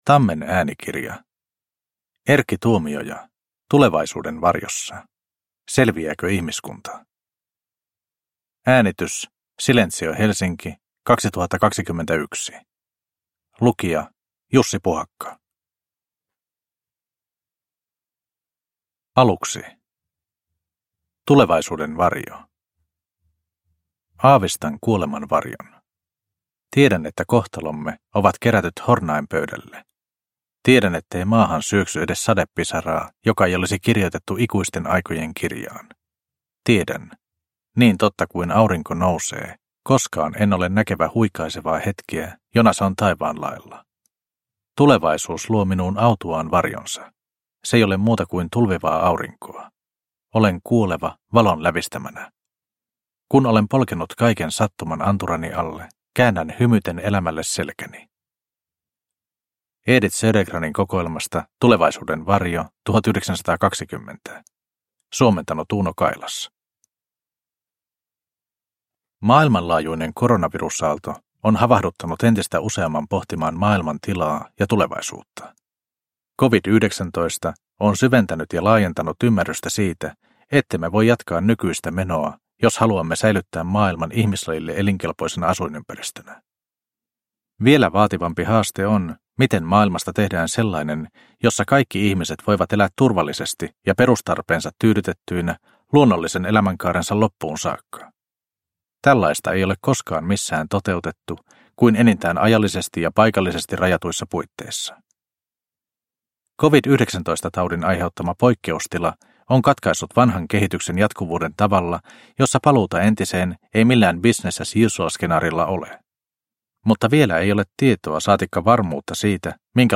Tulevaisuuden varjossa – Ljudbok – Laddas ner